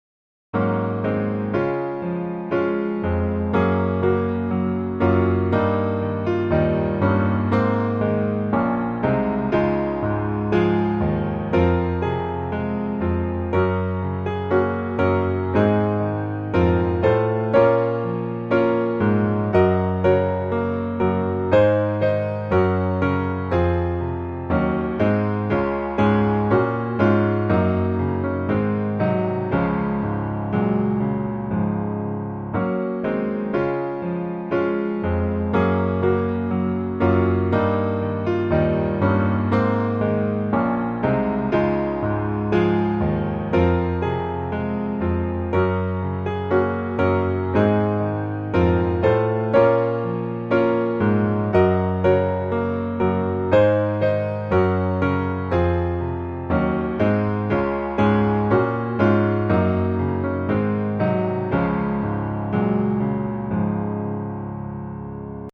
Db Majeur